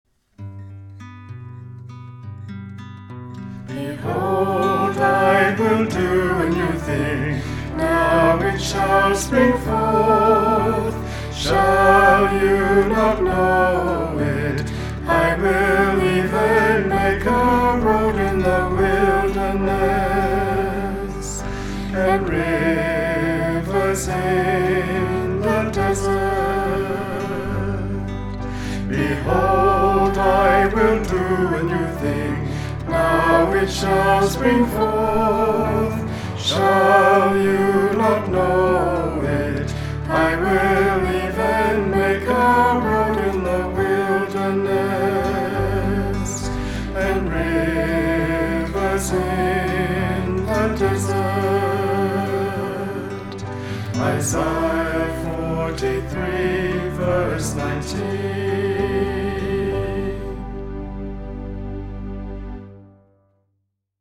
Vocalists
Piano
Keyboard
Guitar